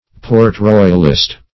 Search Result for " port-royalist" : The Collaborative International Dictionary of English v.0.48: Port-royalist \Port-roy"al*ist\, n. (Eccl.
port-royalist.mp3